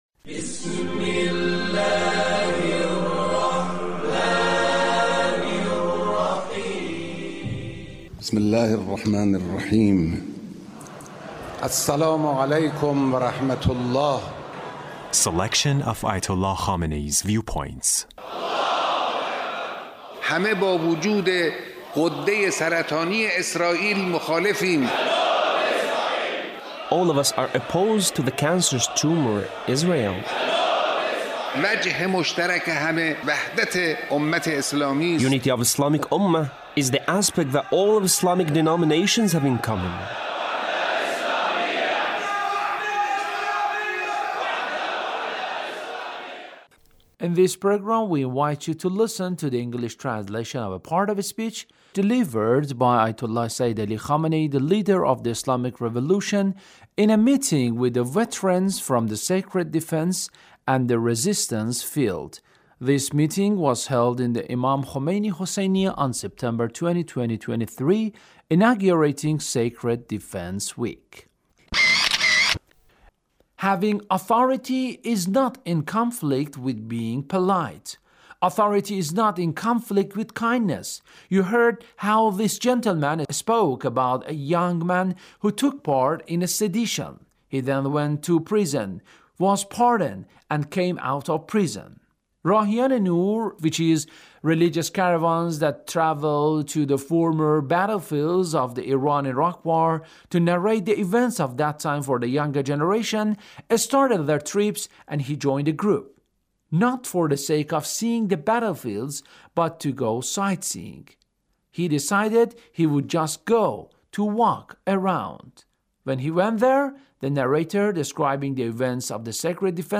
Leader's Speech (1863)